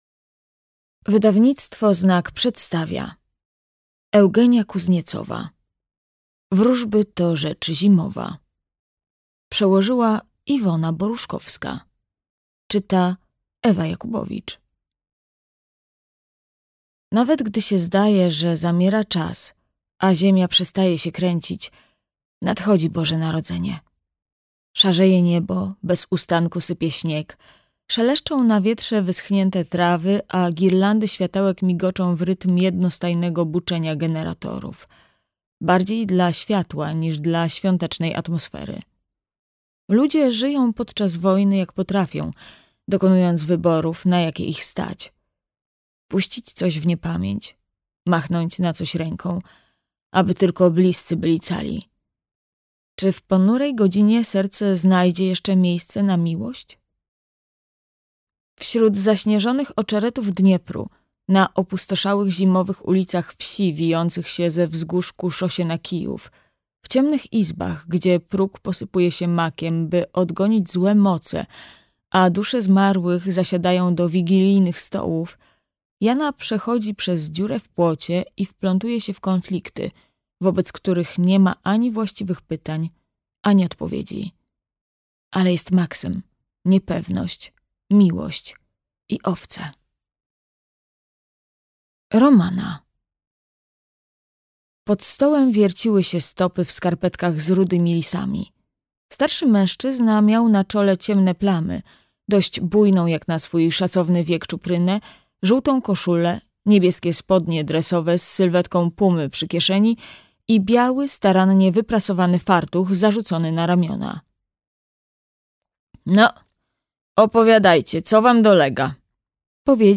Wróżby to rzecz zimowa - Eugenia Kuzniecowa - audiobook + książka